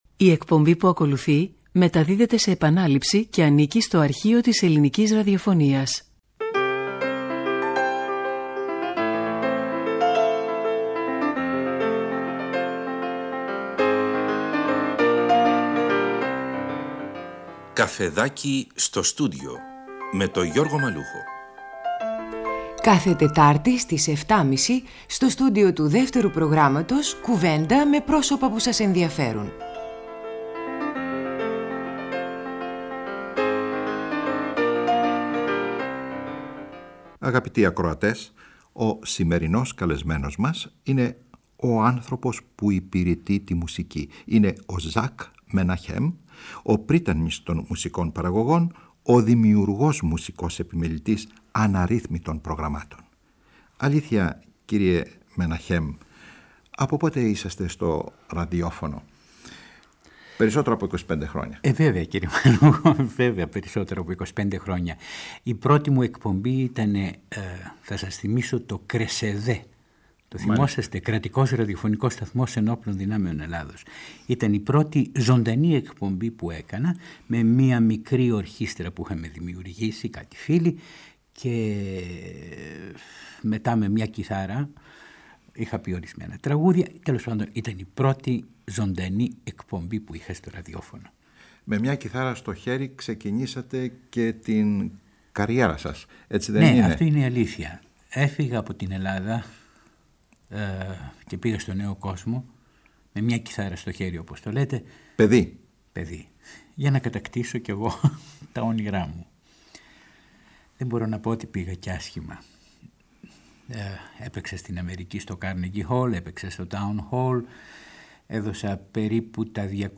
Στα μουσικά διαλλείματα της συζήτησης μας συστήνει με τον δικό του ιδιαίτερο τρόπο μελωδίες και τραγούδια της Λατινικής Αμερικής.
ΣΥΝΕΝΤΕΥΞΕΙΣ